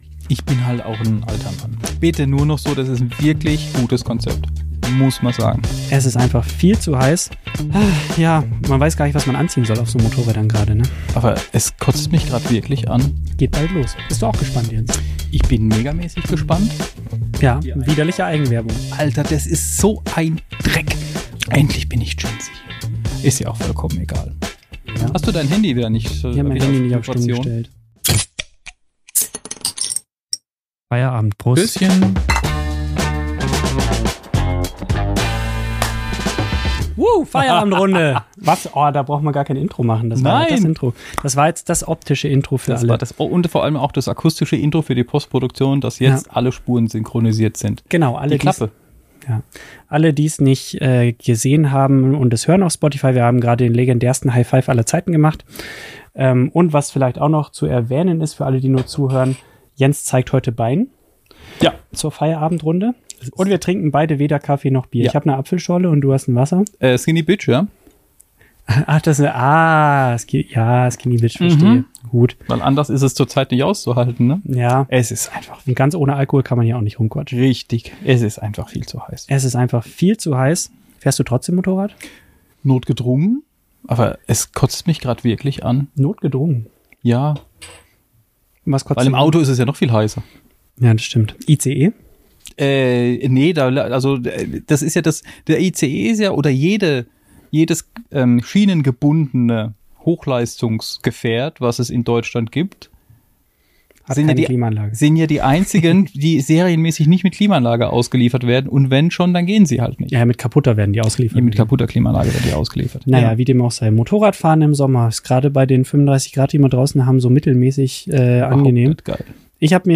Die Moderatoren teilen persönliche Erfahrungen und geben praktische Tipps zum Fahren in der warmen Jahreszeit.